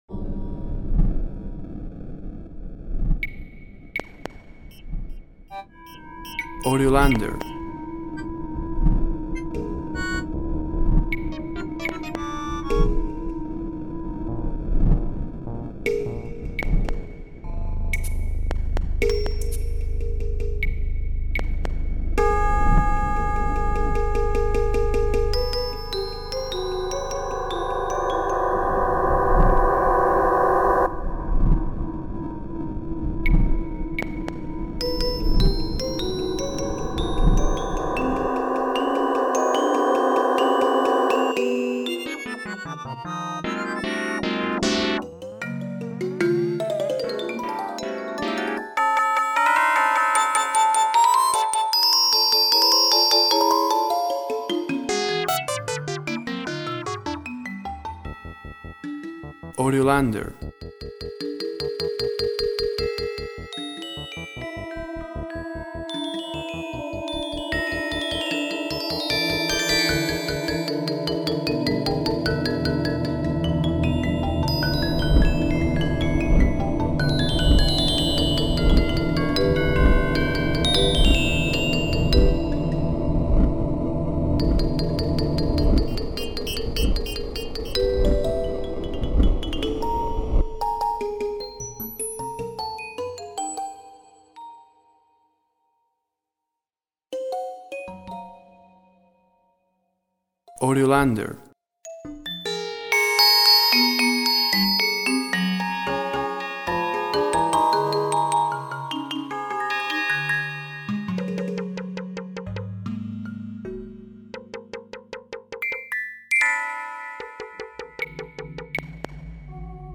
Sections would work well for a horror, sci-fi application.